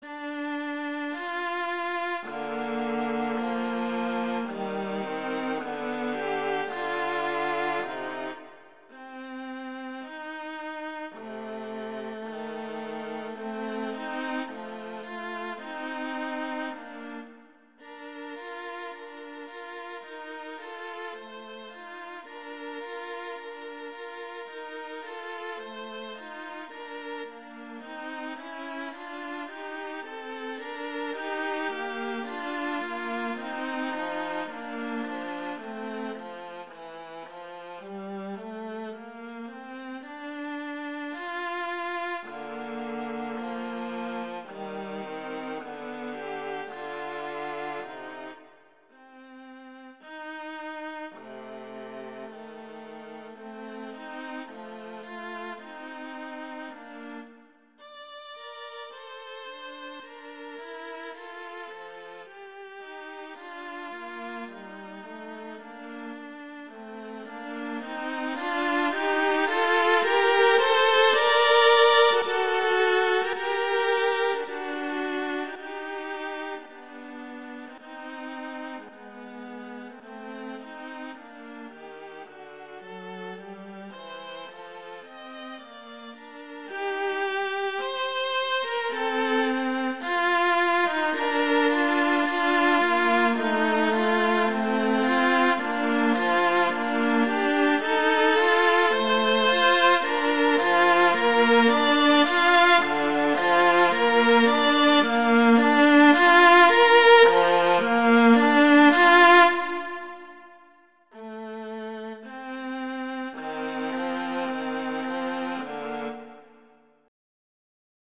classical, french, children
F major, Bb major